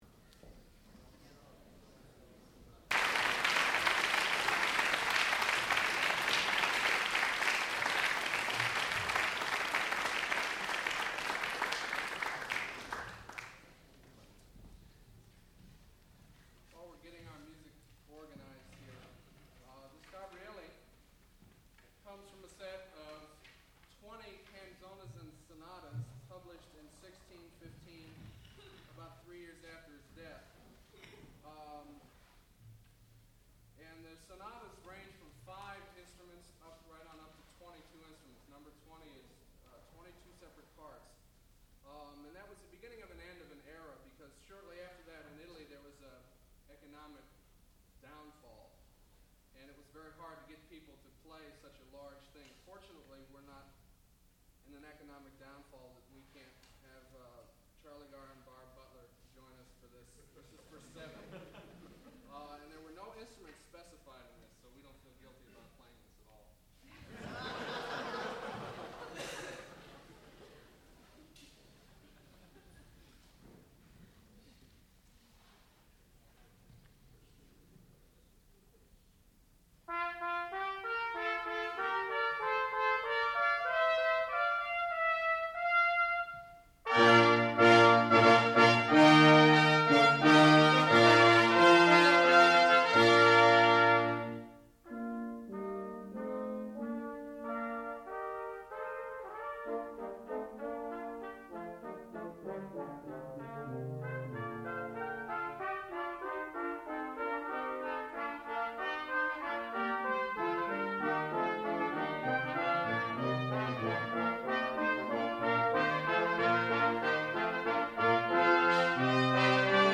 sound recording-musical
classical music
trumpet